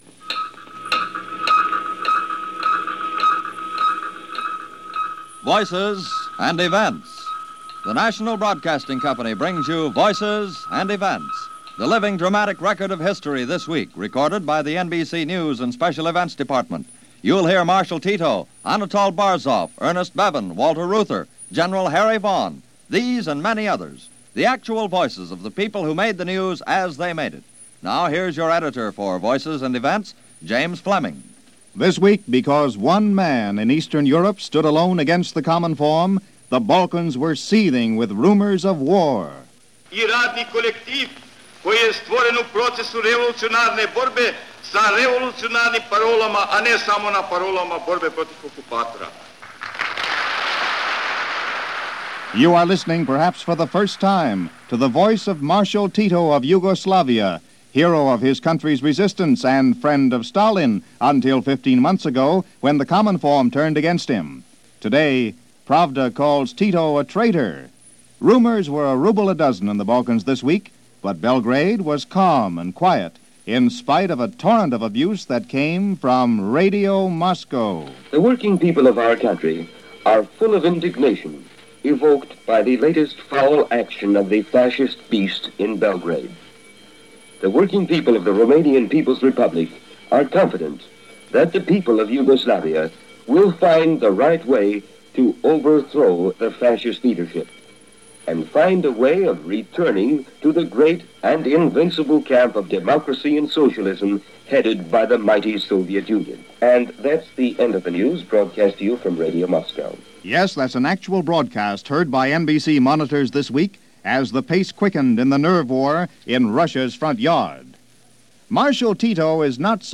NBC News – Voices And Events – September 4, 1949